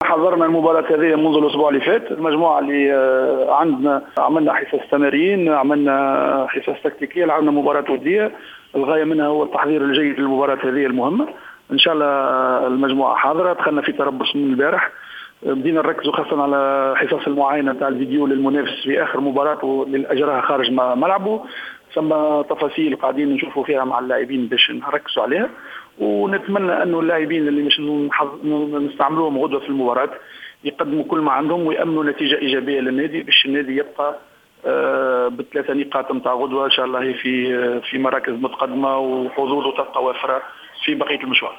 ندوة صحفية للنادي الصفاقسي